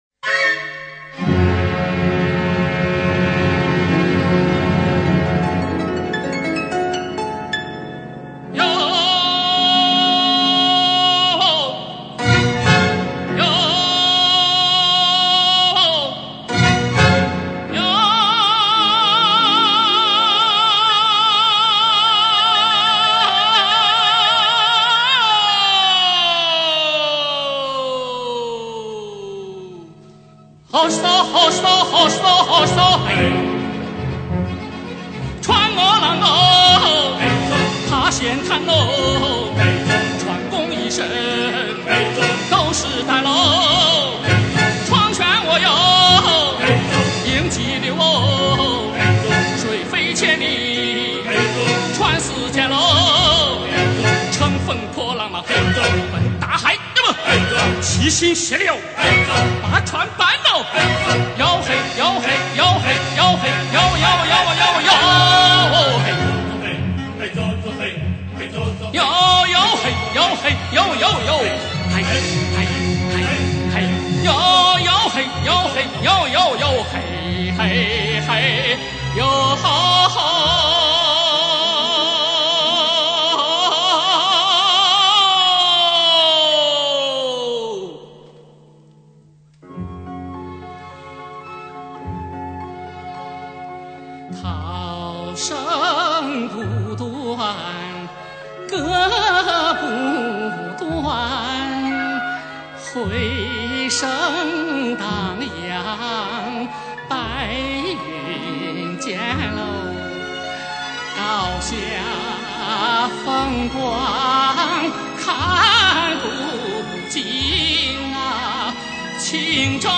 演唱热情奔放，富有乐感，吐字清晰，声音流畅，高音稳定、透明，辉煌而华丽。